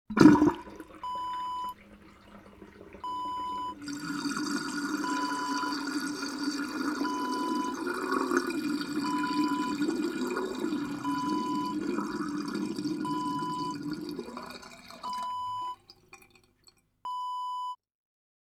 Emptying Sink Wav Sound Effect
Description: A rubber drain plug is pulled up, water runs out
Properties: 48.000 kHz 16-bit Stereo
A beep sound is embedded in the audio preview file but it is not present in the high resolution downloadable wav file.
emptying-sink-preview-1.mp3